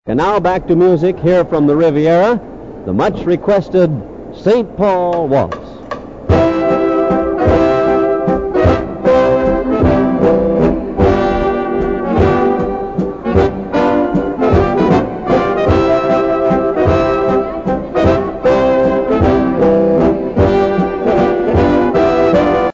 Live radio broadcasts of the performances were sent over the local airways from here and many early broadcasters spent many a dance inside this glass "goldfish" bowl.
riviera_waltz.mp3